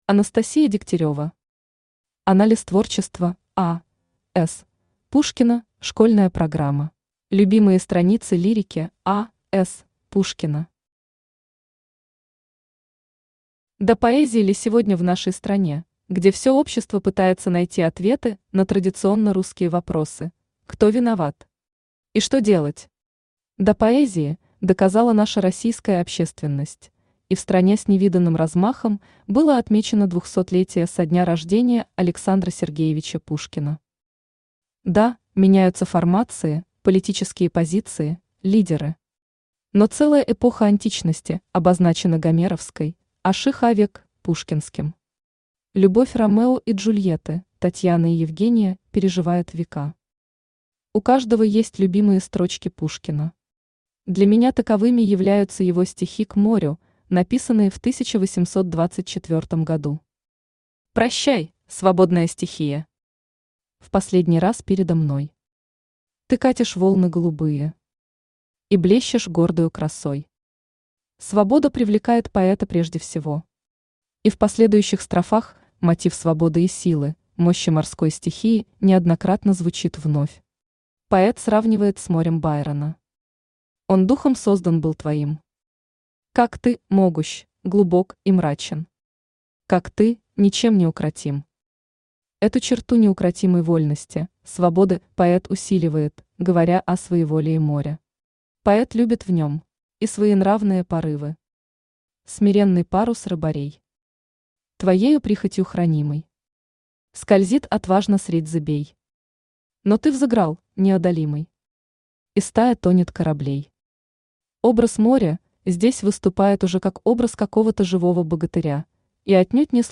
Аудиокнига Анализ творчества А. С. Пушкина (школьная программа) | Библиотека аудиокниг
Читает аудиокнигу Авточтец ЛитРес.